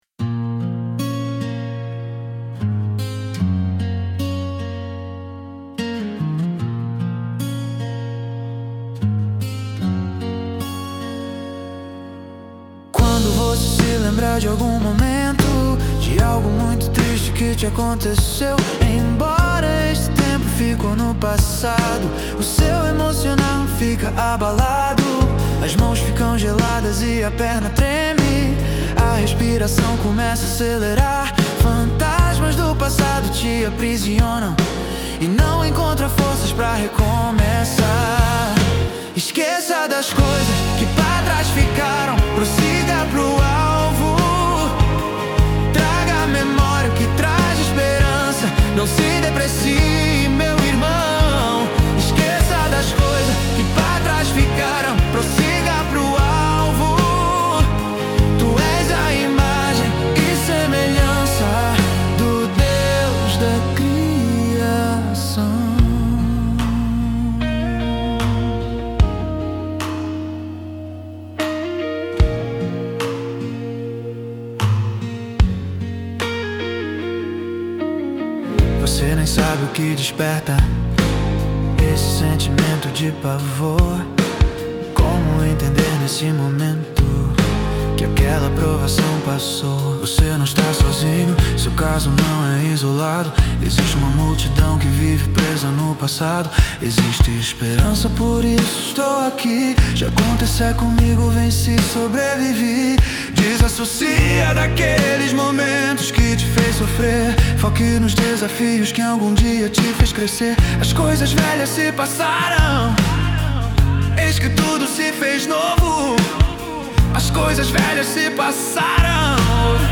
EstiloSoul